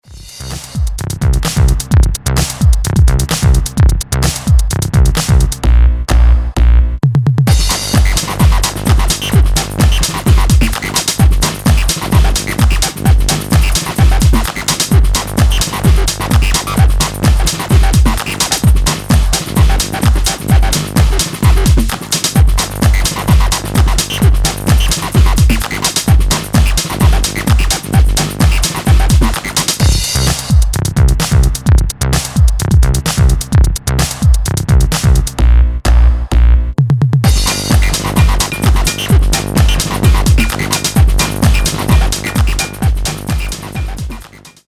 most dancefloor rockin’
Neo Balearic Acid/Italo disco